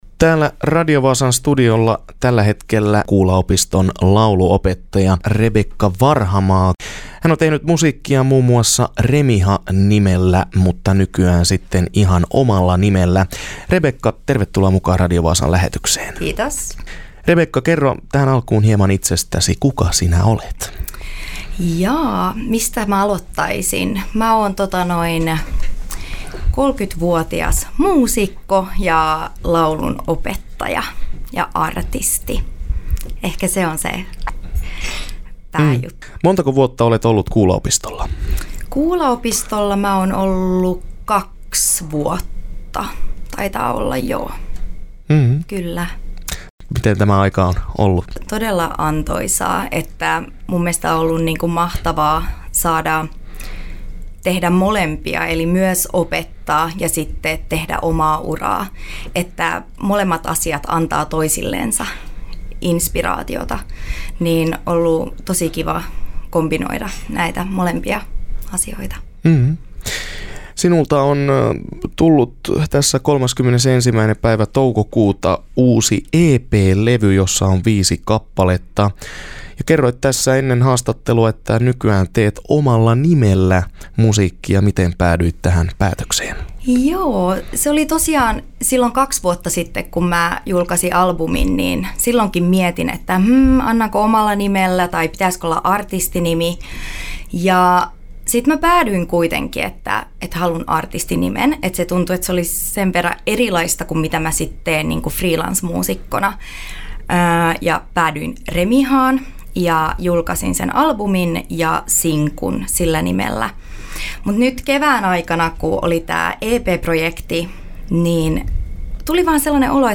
haastattelee